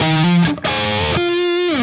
AM_HeroGuitar_130-F02.wav